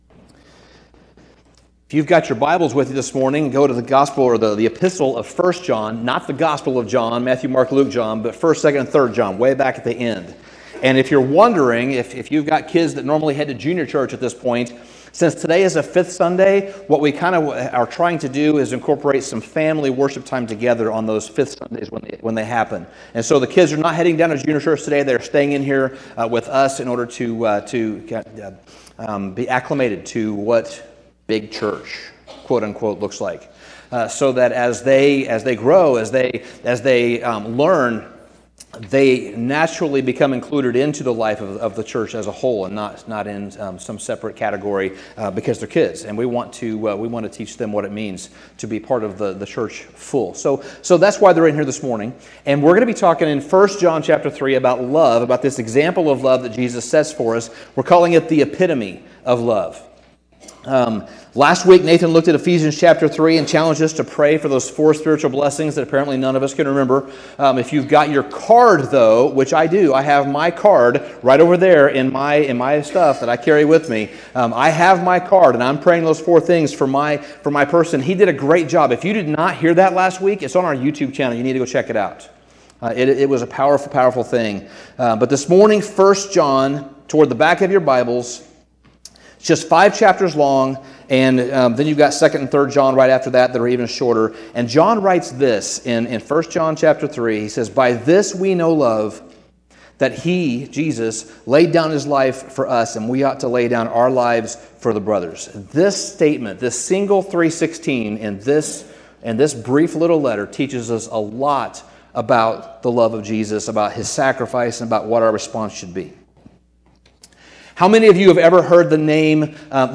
Sermon Summary If John 3:16 describes God's love for us, then 1 John 3:16 provides an example of what that love looks like in a tangible way, and how we should emulate it. In John's shorter first epistle, he describes the love of Christ, and from his description, we gain some insights into what love is, and how we can live our lives as he did, loving one another, and living sacrificially because of that love.